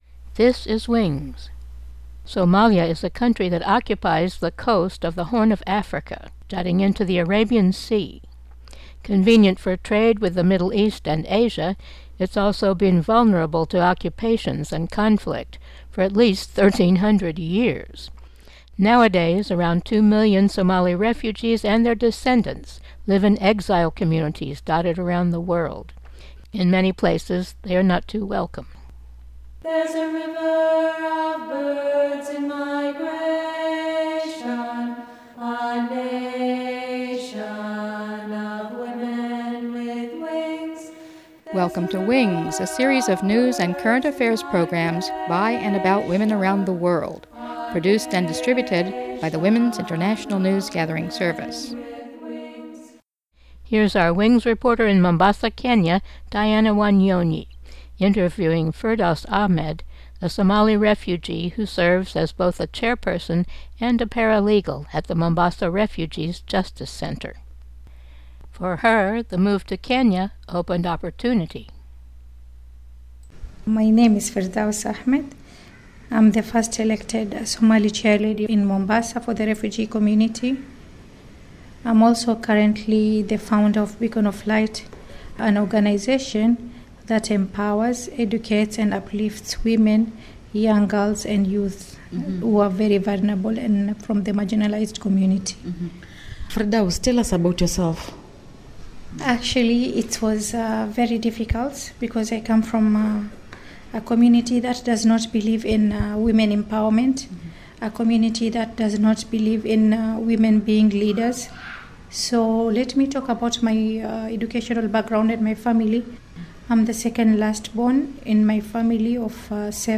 Subtitle: Women emerging to lead Program Type: Weekly Program